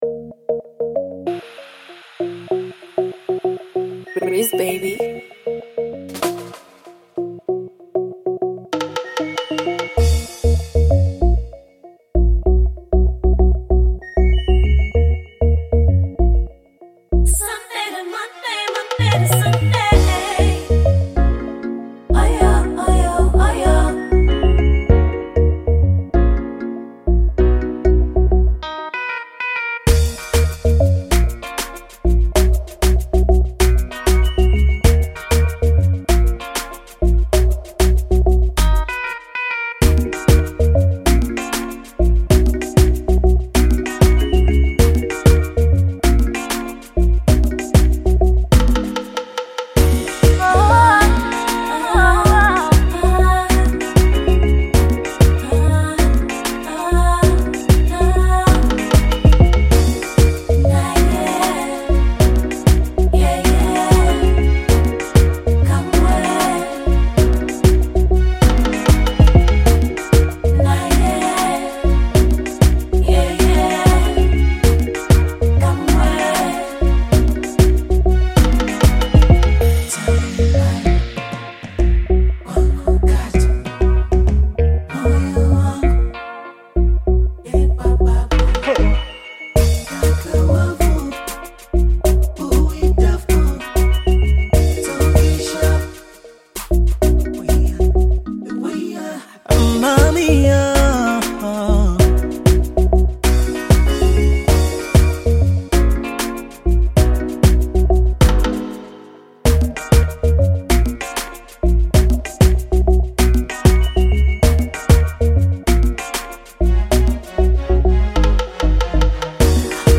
collabo with two of the baddest vocalists out of Tanzania